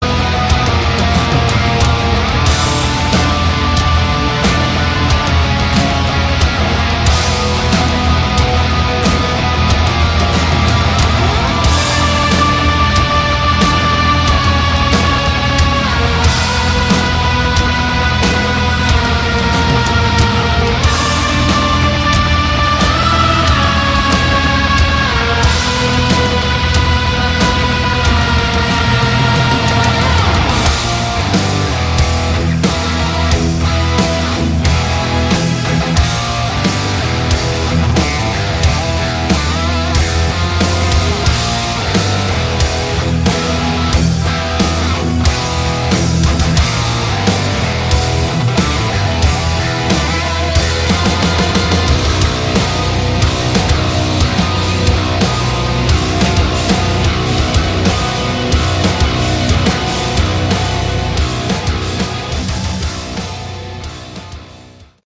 Category: Prog Rock
vocals
guitars
bass
drums
keyboards